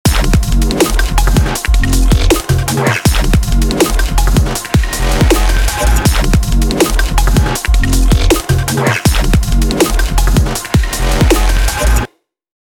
DTM